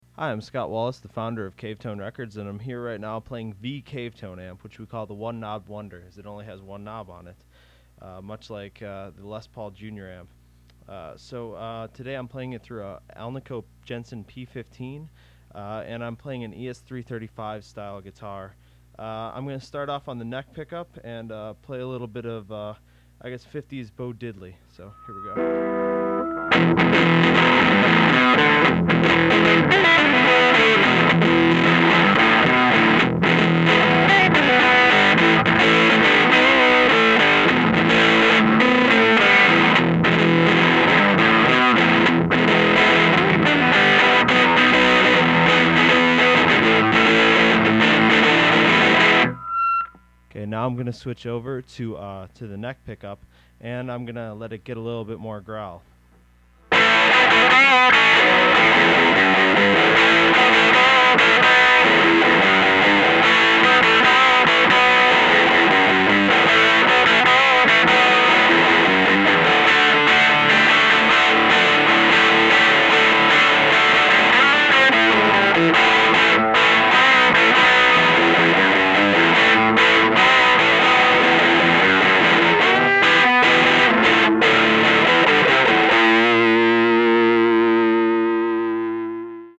Thee Cavetone Tube Guitar Amplifier
Thee Cavetone is everything great about a 1950s guitar amp taken to a whole new level. You haven't heard snarling gain until you've heard a Cavetone.
Lancaster-Thee-Cavetone-amp-mp3.mp3